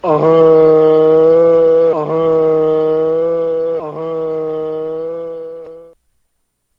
Play, download and share Fonzie Honk original sound button!!!!
fonzie-honk.mp3